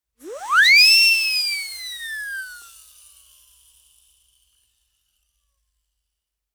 Siren_Whistle.mp3